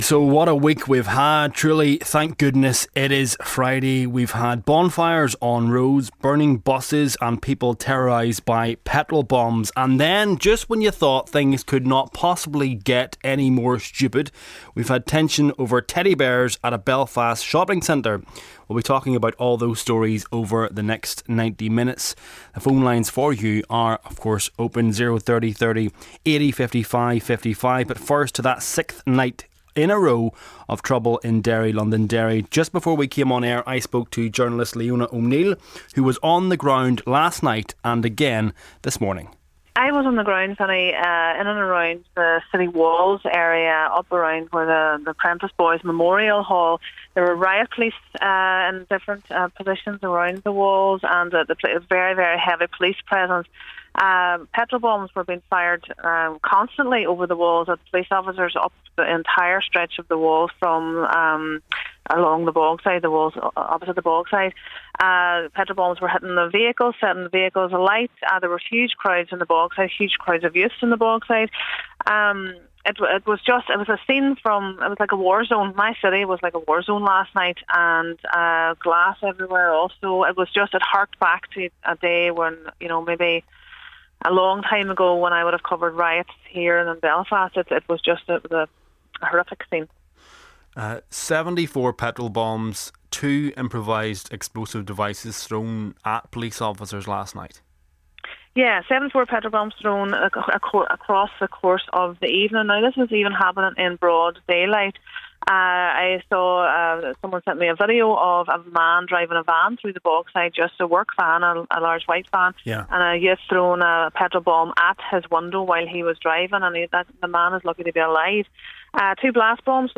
We also got reaction from SDLP leader Colum Eastwood, DUP MLA Gary Middleton, Sinn Fein MP Elisha McCallion and former assistant chief constable Alan McQuillan.